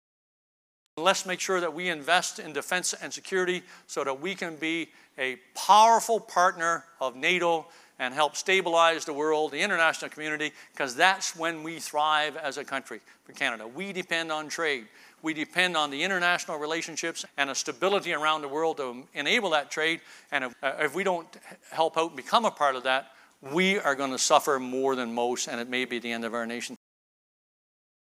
Canadian Armed Forces Retired General Rick Hillier was the keynote speaker at the Saskatchewan Crops Conference in Saskatoon on Tuesday.